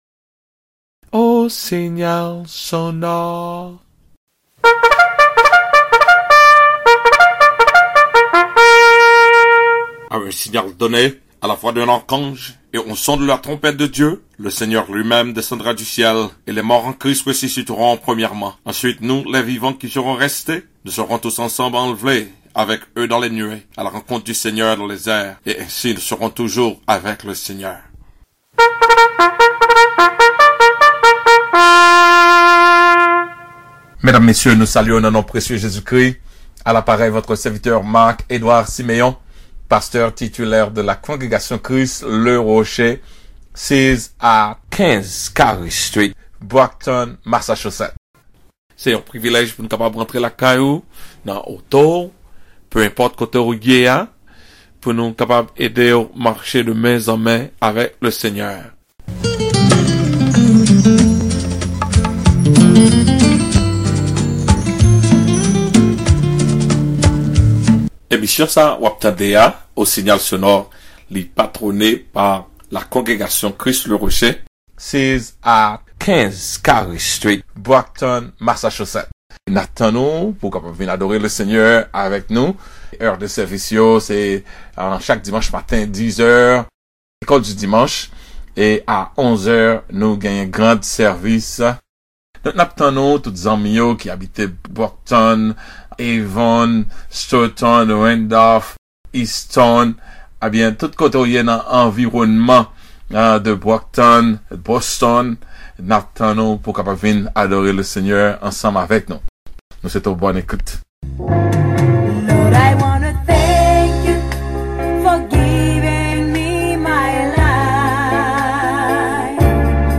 please click herer to download: AVEUGLE NE SERMON 2020